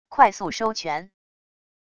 快速收拳wav音频